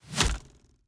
Bow Attacks Hits and Blocks
Bow Blocked 2.wav